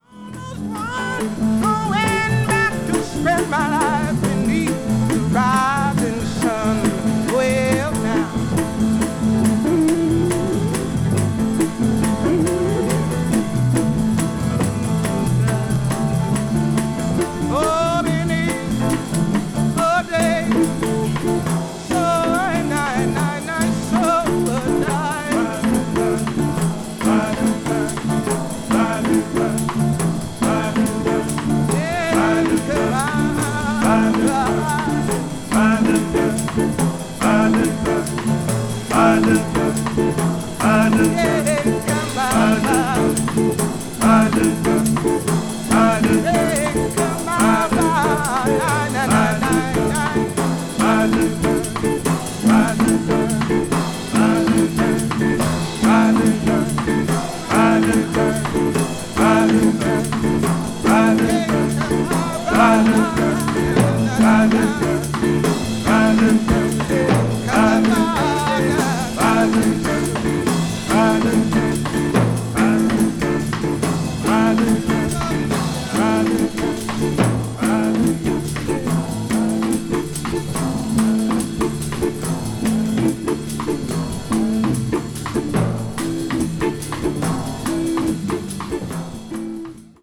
1968年に出演したMontreux Jazz Festivalでのライブ・レコーディング音源を収録。